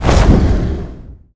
Fire Explosion 2.wav